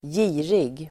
Uttal: [²j'i:rig]